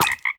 sounds / mob / frog / hurt1.ogg
hurt1.ogg